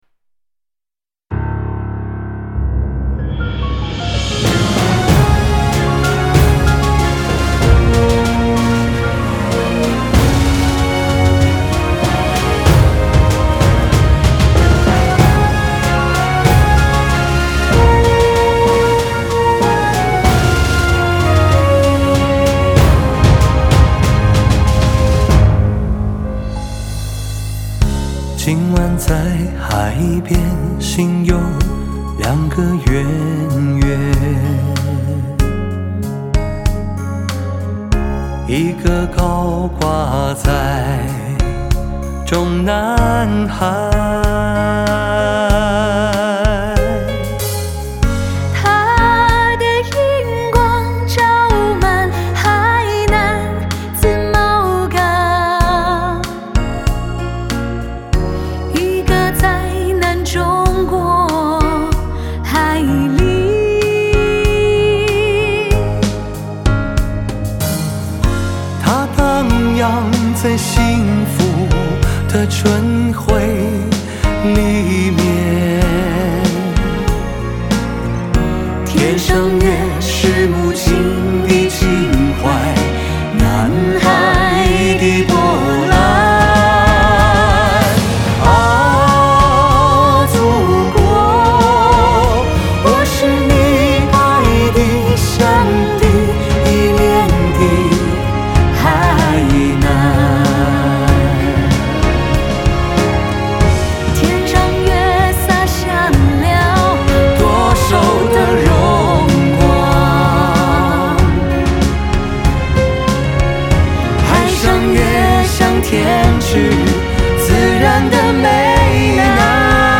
壮阔的歌词搭配激昂的曲调，让人不由自主的为祖国的今天而自豪！